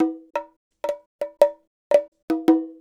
Bongo 07.wav